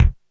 Kick 02.wav